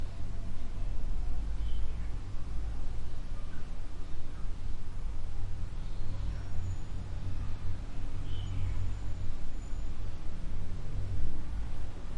乌干达 " 房间音小远处的风通过树木和交通从打开的窗户短坎帕拉，乌干达，非洲2016年
描述：房间口气小遥远的风通过树木和交通从开放窗口短坎帕拉，乌干达，非洲2016.wav